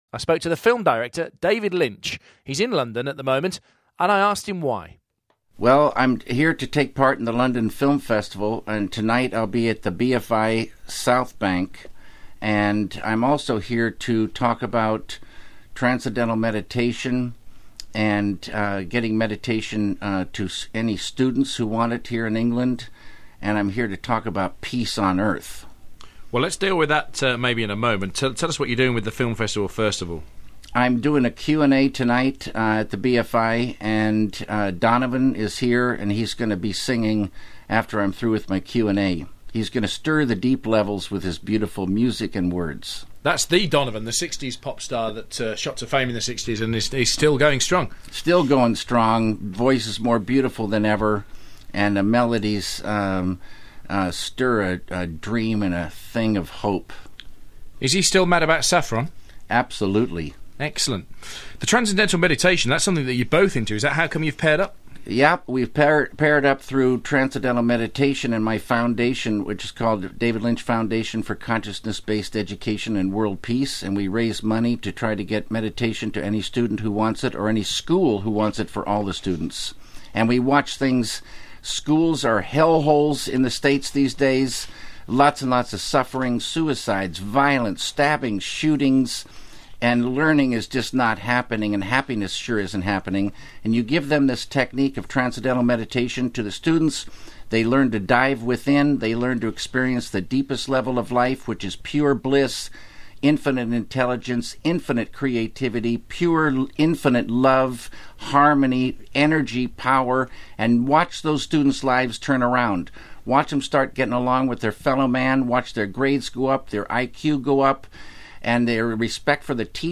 As an accompaniment, here is an interview from October 23, 2007 for the Simon Mayo program Daily Mayo on BBC 5 Live.